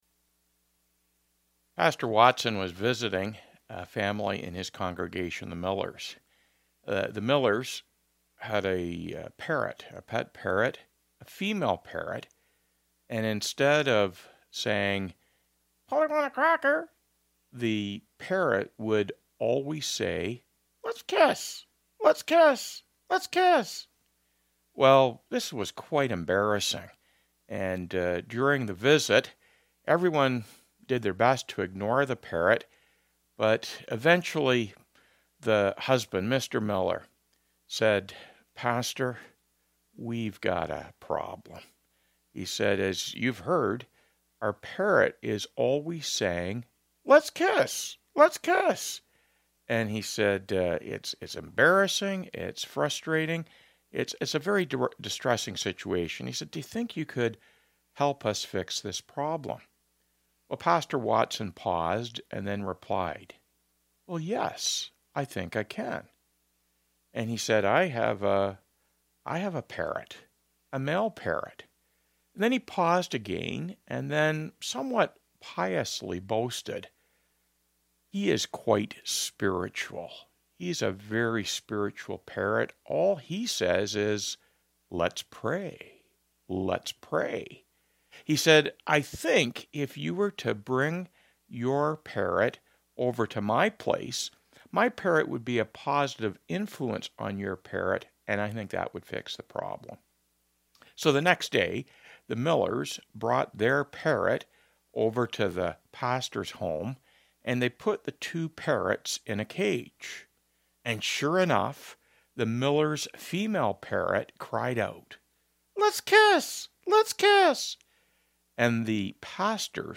Past Sermons - Byron Community Church